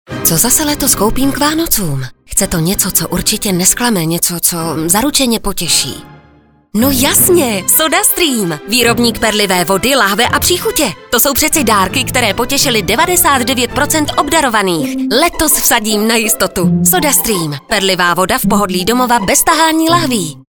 Do konce Vánoc budou na stanicích Evropa 2, Blaník, Frekvence 1 a Rádio Kiss vysílány dva dvacetisekundové spoty v rovnoměrné rotaci.